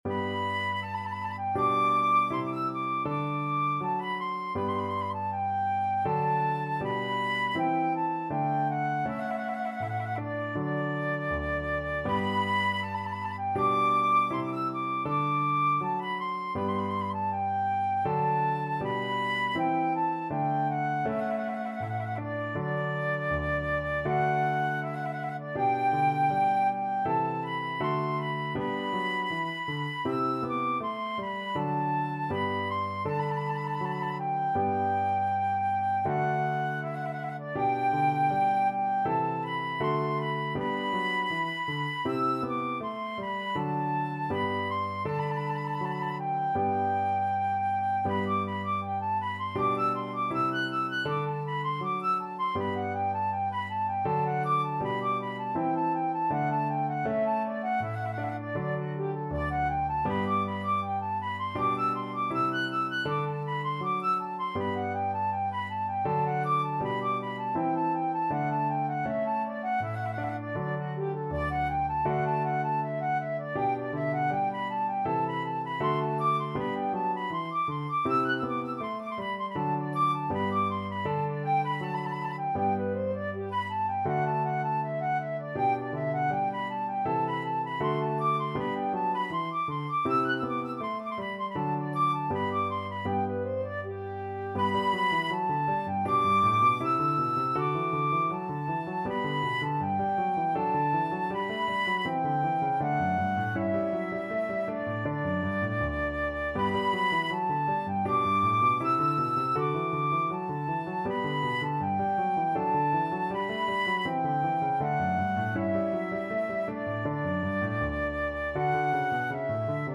4/4 (View more 4/4 Music)
Allegretto =80